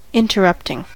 interrupting: Wikimedia Commons US English Pronunciations
En-us-interrupting.WAV